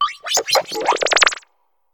Cri de Germéclat dans Pokémon HOME.